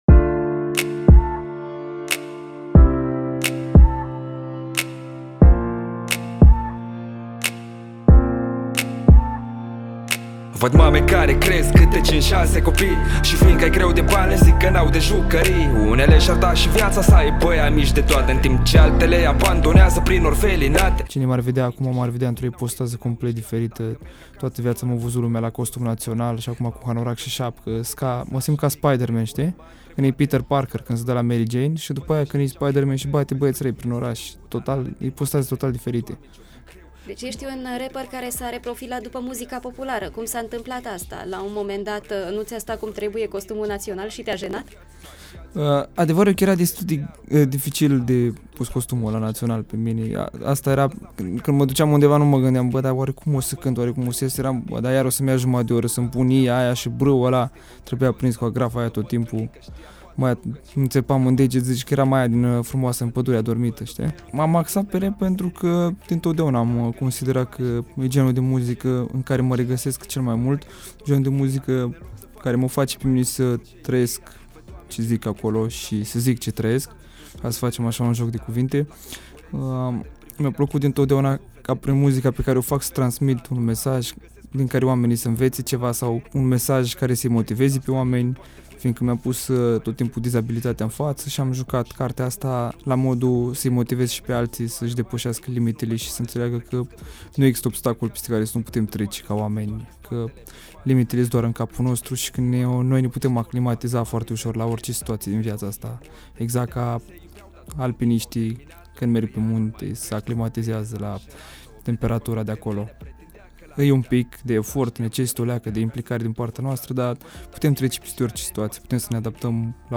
Incluziv: Cu sufletu-n privire: interviu EXCLUSIV
Nu se întâmplă prea des să primească omul versuri personalizate de la un invitat. Ce fel de versuri nu vă spun, e mai interesant să le auziţi direct de la cel care le-a compus.